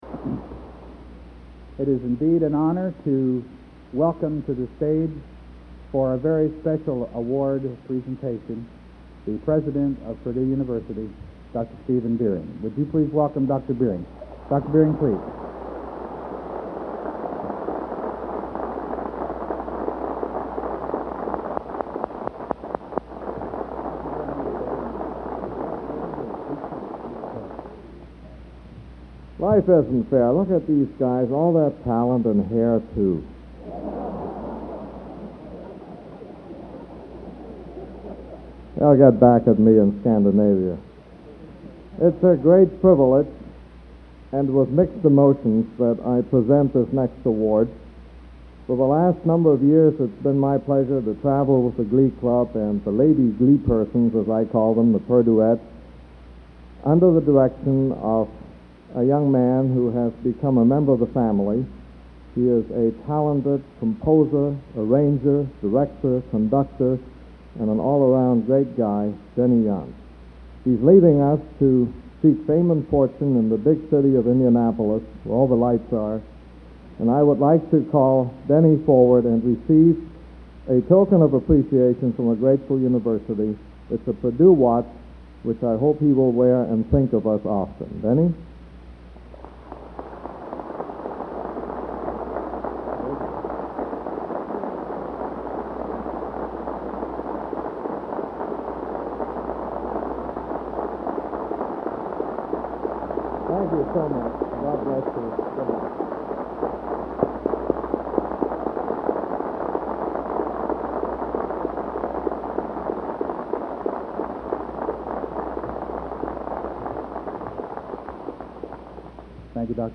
Location: West Lafayette, Indiana
87-EOS-32-PresidentBeeringPresentsAwards.mp3